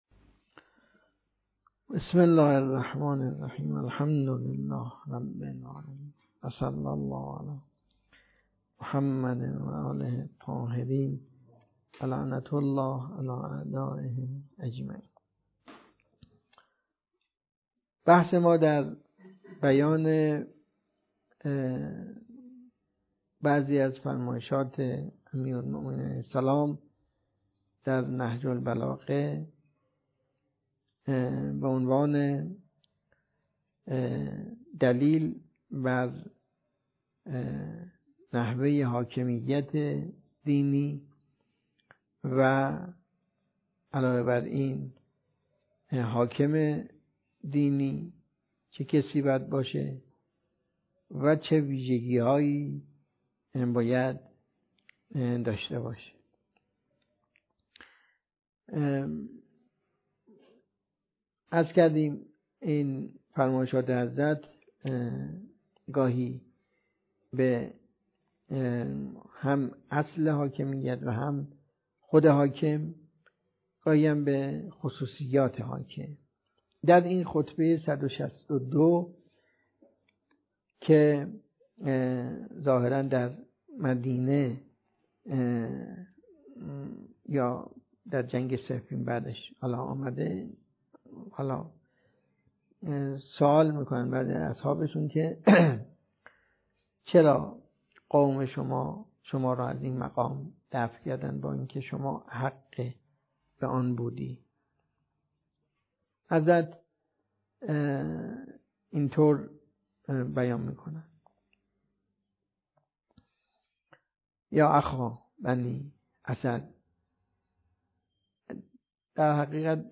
درس خارج